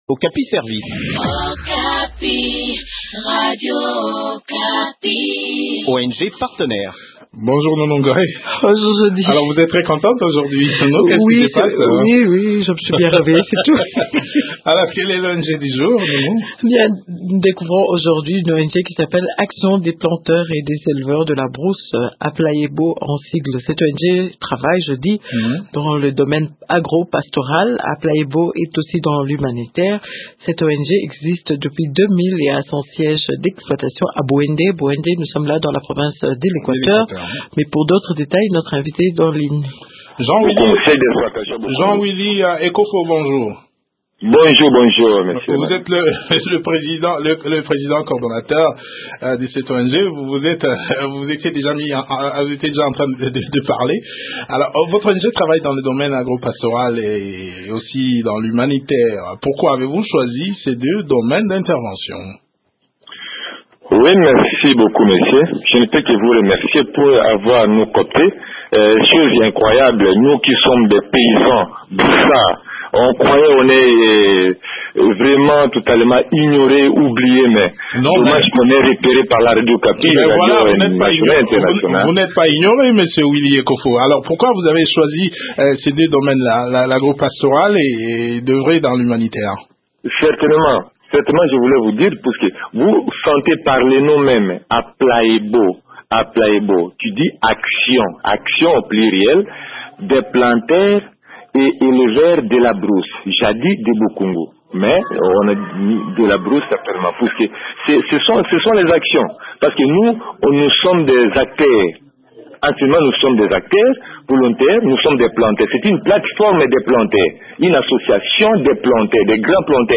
Le point des activités de cette ONG dans cet entretien